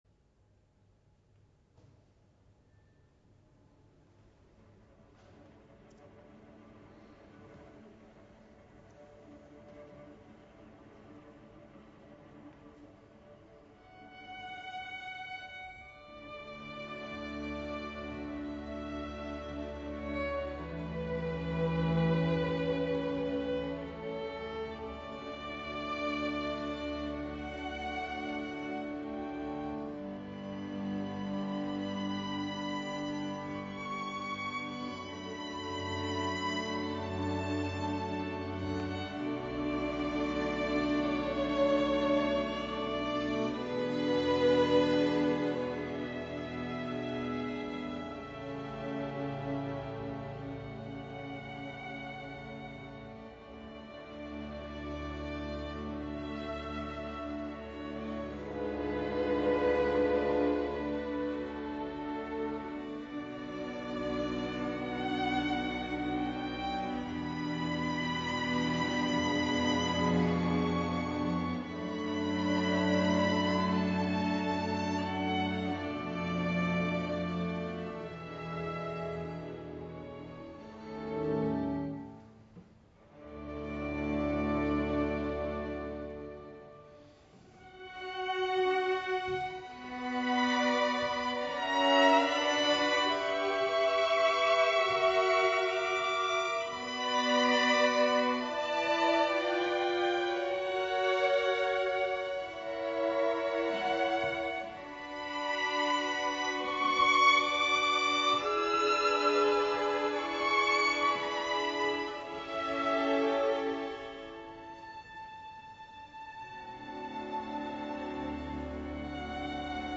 Live in Dover. Maison Dieu (Town Hall) 11 October 2013